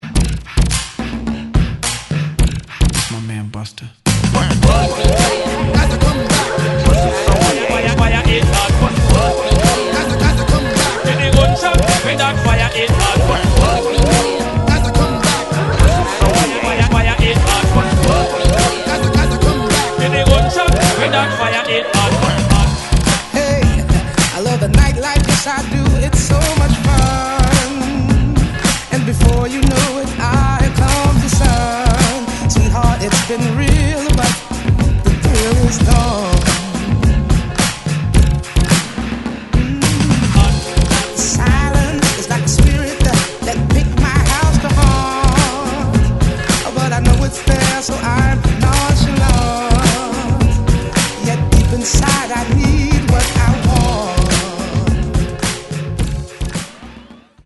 90's
Clean